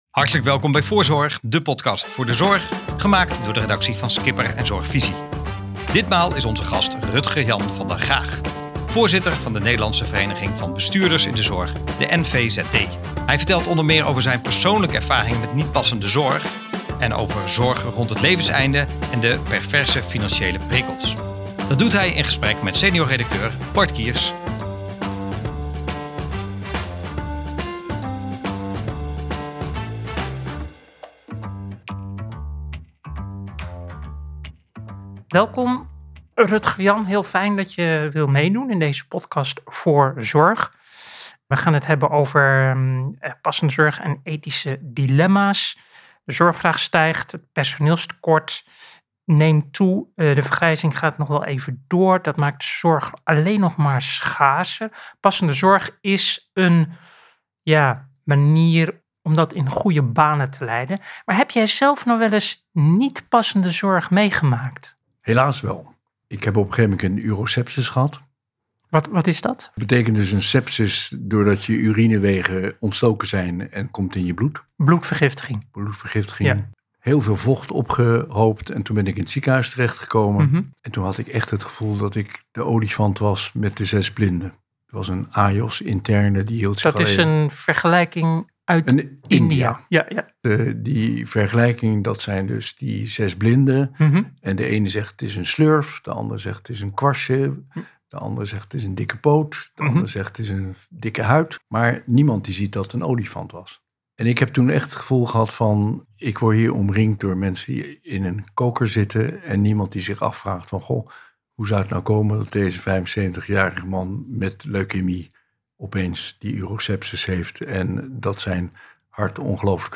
Voorzorg is de podcast over de gezondheidszorg in Nederland, gemaakt door de redactie van Skipr en Zorgvisie. Met nieuws, interviews en reportages uit de zorg.